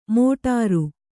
♪ mōṭāru